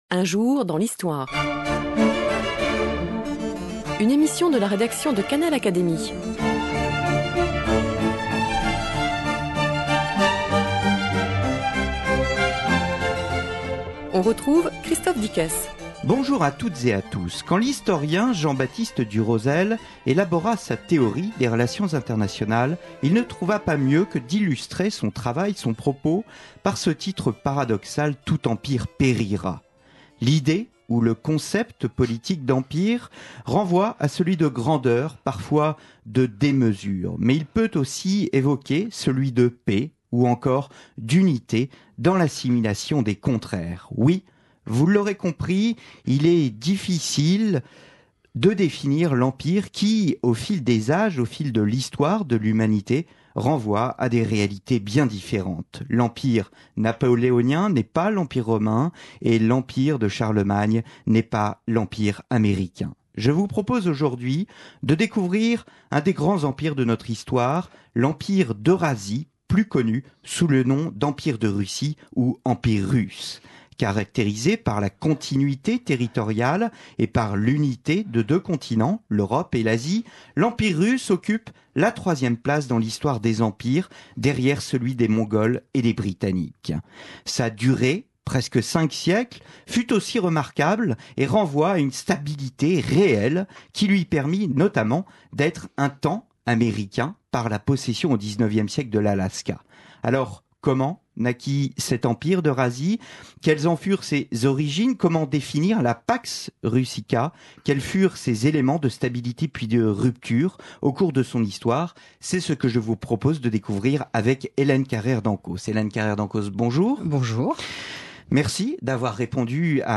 Cette émission a été enregistrée début 2006, peu après la publication de l'ouvrage d'Hélène Carrère d'Encausse.